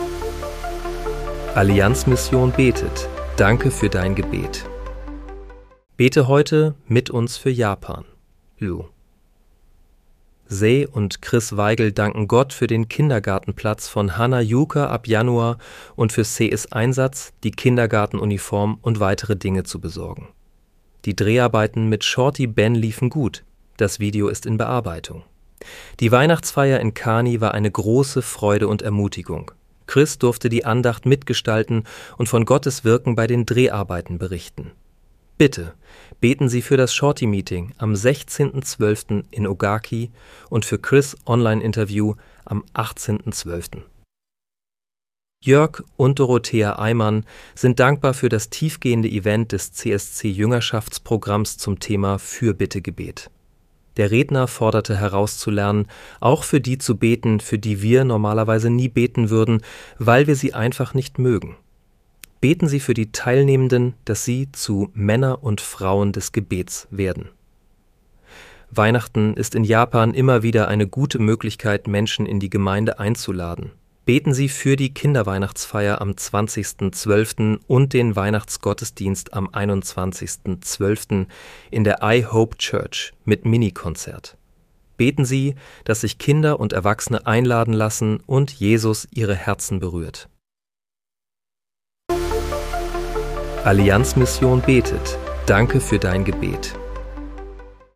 Bete am 16. Dezember 2025 mit uns für Japan. (KI-generiert mit der